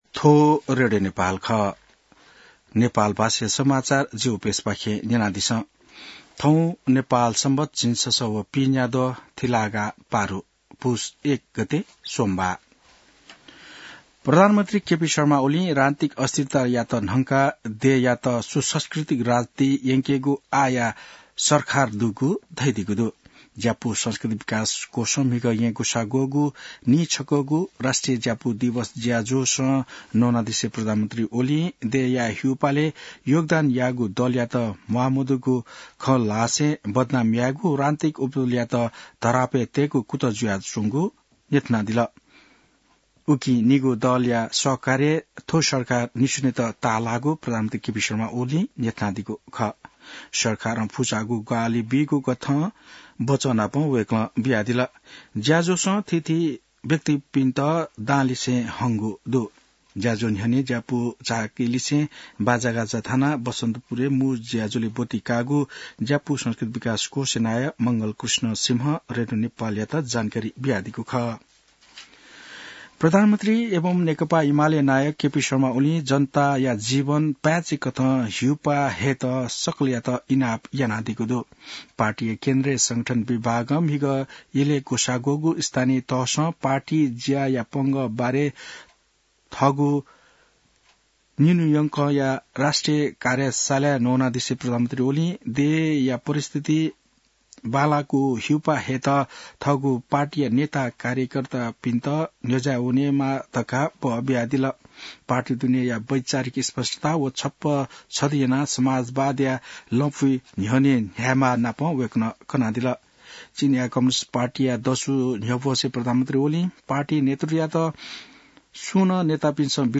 नेपाल भाषामा समाचार : २ पुष , २०८१